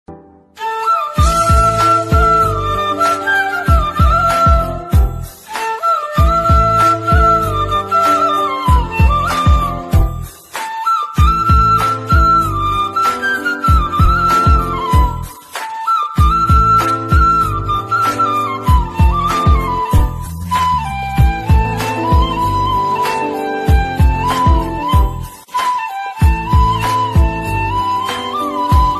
Flute Ringtones